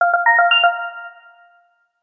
06304 message ding 1
ding incoming interface message notification sfx sound sound effect free sound royalty free Sound Effects